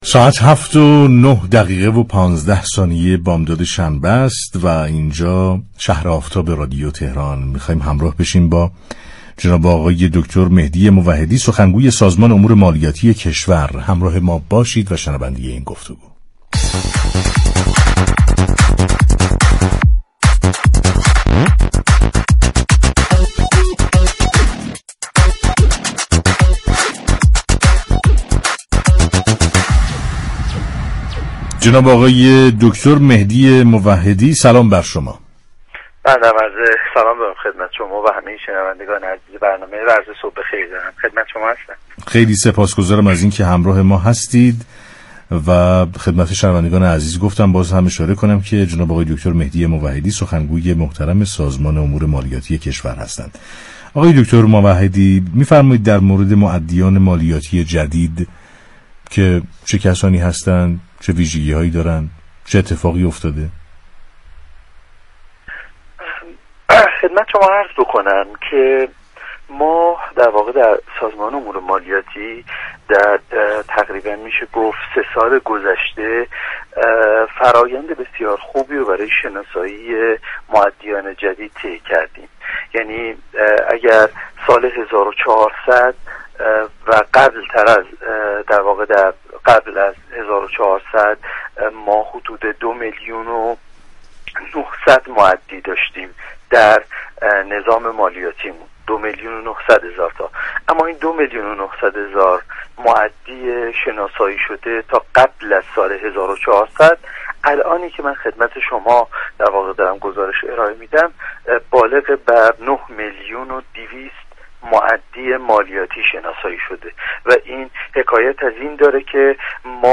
در گفت و گو با «شهر آفتاب» اظهار داشت